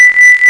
sonrloop.mp3